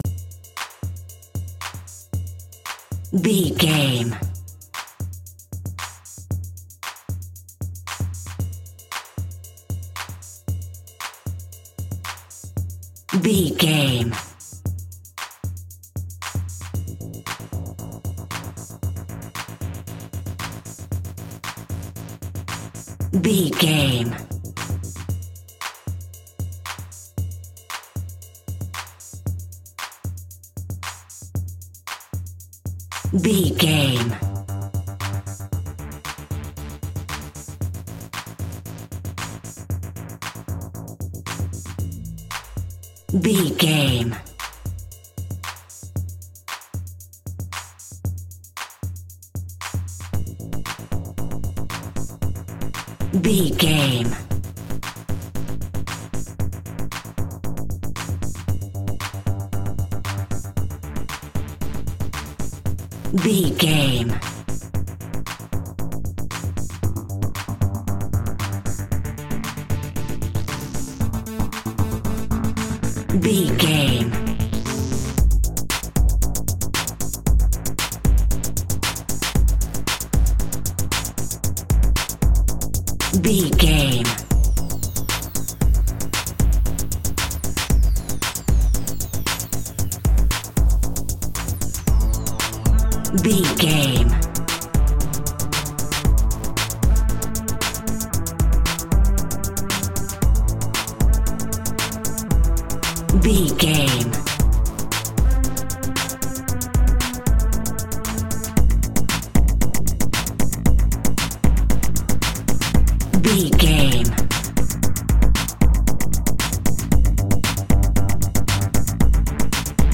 Epic / Action
Fast paced
Aeolian/Minor
groovy
dark
futuristic
synthesiser
drum machine
breakbeat
energetic
power rock
power pop rock
synth lead
synth bass
synth drums